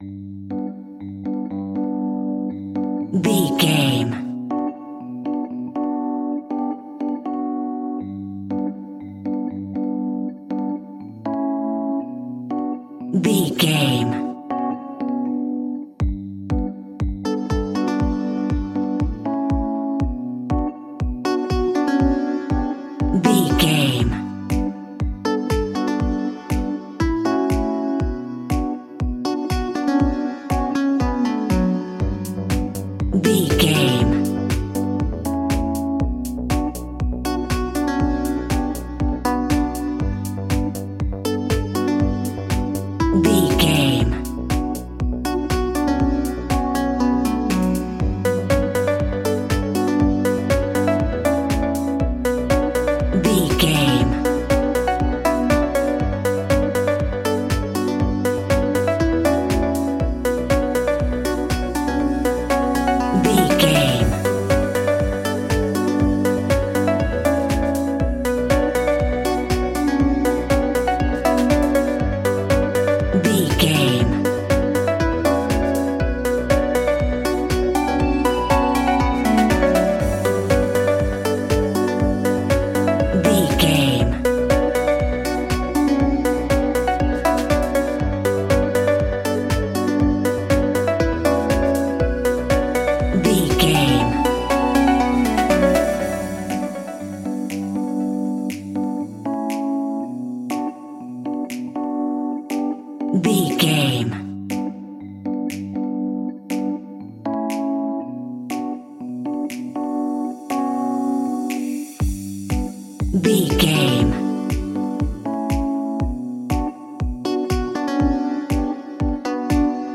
Aeolian/Minor
Slow
groovy
peaceful
tranquil
meditative
smooth
drum machine
electric guitar
synthesiser
synth pop
synth leads
synth bass